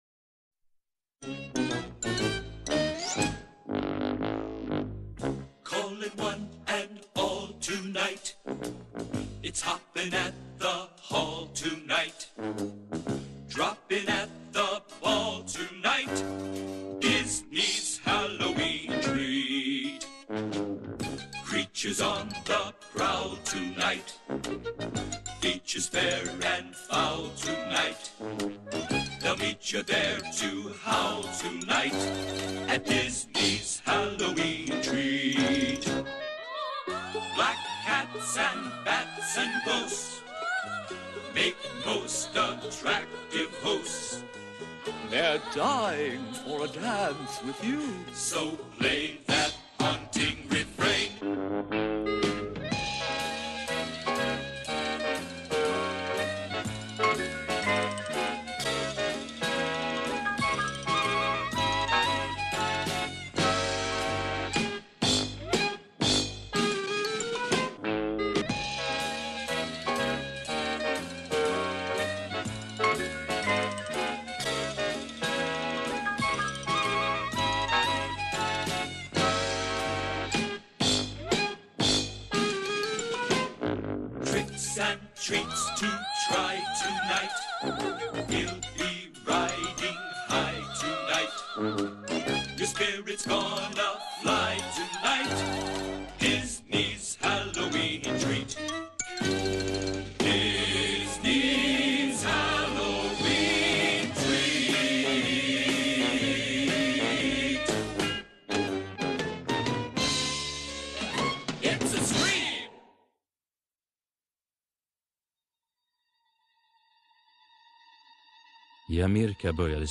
Tecknat Barn Svenska:A Disney Halloween (1989 Walt Disney Pictures AB) VHSRIPPEN (Svenska) Hela Filmen (4D)